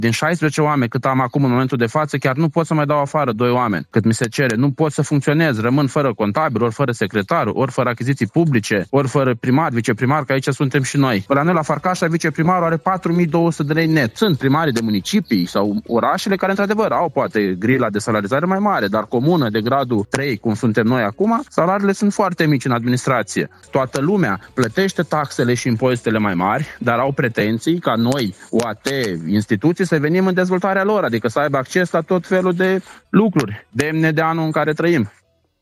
Insert audio Bogdan Tifui, primarul comunei Fărcașa